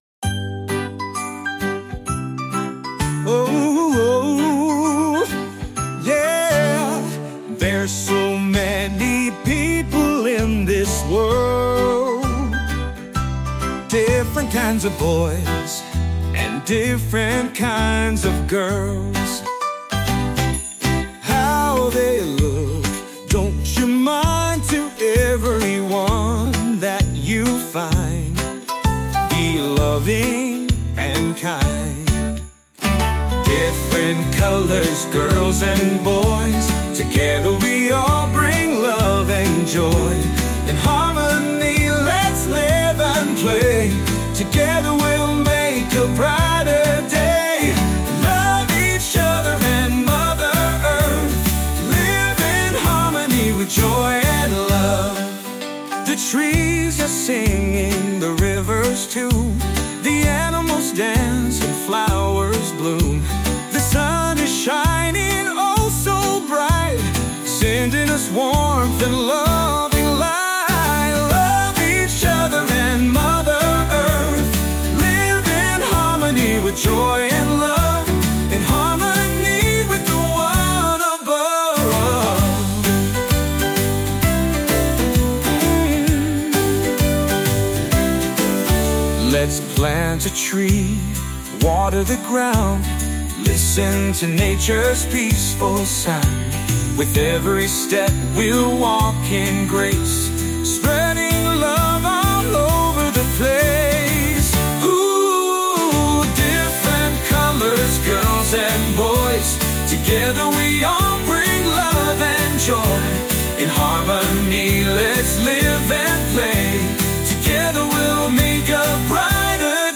a joyful, uplifting song